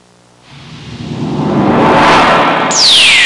Future Intro Sound Effect
Download a high-quality future intro sound effect.
future-intro.mp3